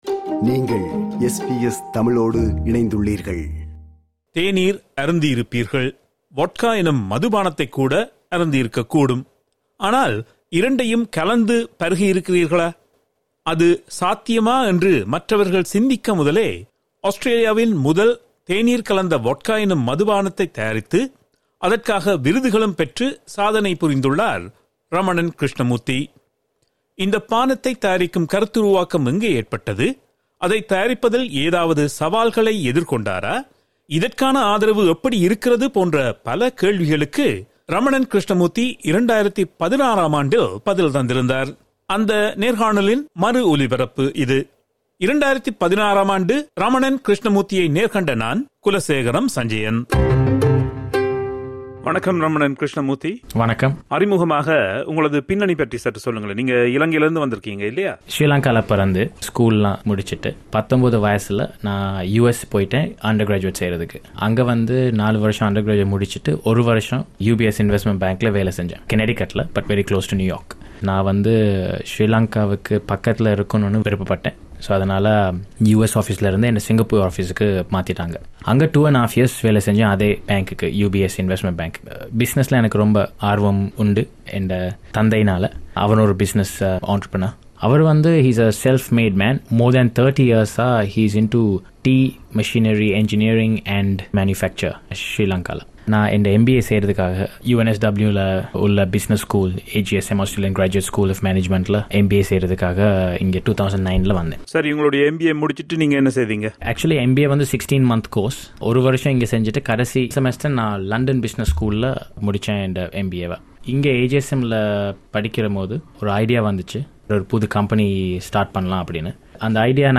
அந்த நேர்காணலின் மறு ஒலிபரப்பு இது.